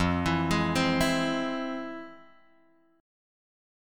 F Minor Major 7th